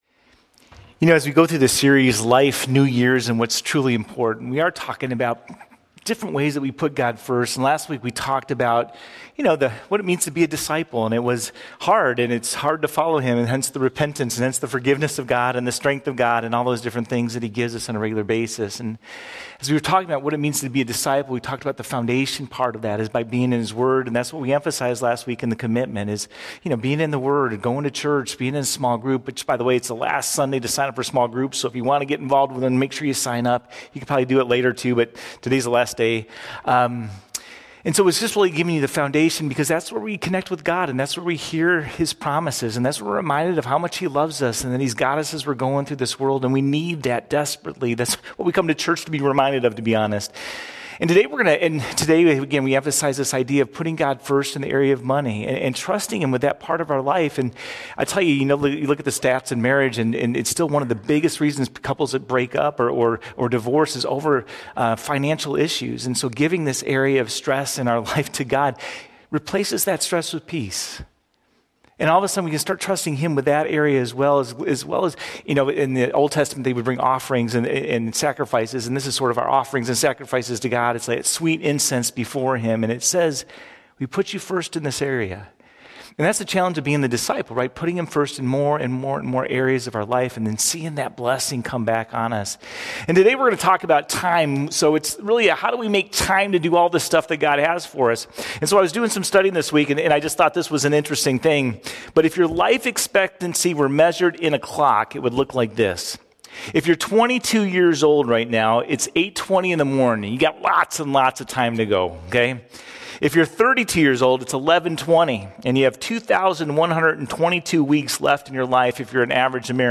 01-19-Sermon.mp3